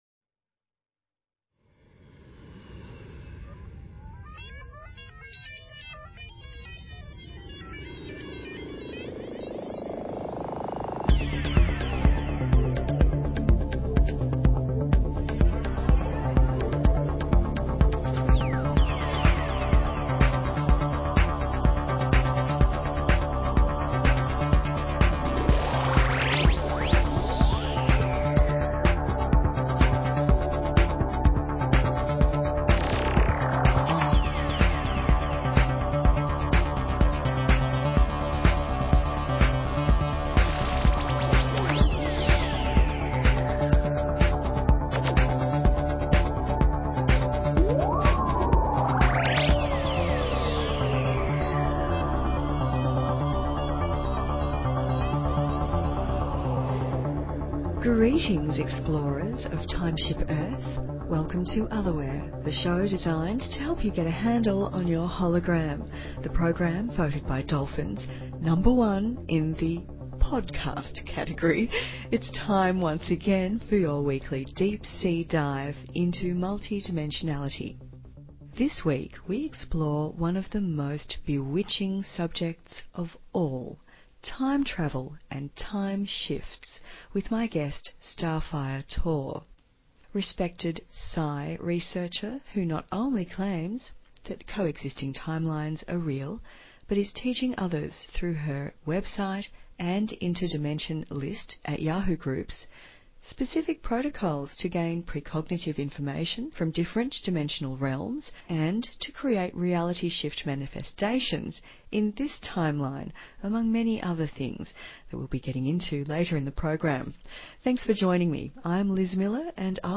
Talk Show Episode, Audio Podcast, Otherware and Courtesy of BBS Radio on , show guests , about , categorized as